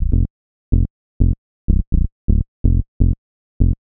cch_bass_frankie_125_Bb.wav